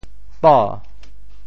“宝”字用潮州话怎么说？
宝（寳） 部首拼音 部首 宀 总笔划 8 部外笔划 5 普通话 bǎo 潮州发音 潮州 bo2 文 潮阳 bo2 文 澄海 bo2 文 揭阳 bo2 文 饶平 bo2 文 汕头 bo2 文 中文解释 潮州 bo2 文 对应普通话: bǎo ①玉器，泛指珍贵的东西：～贝 | ～剑 | ～物 | ～藏（zàng ） | 国～ | 财～ | 珍～ | 传（chuán）家～ | 如获至～ | ～贵。